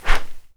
SWISH 1   -S.WAV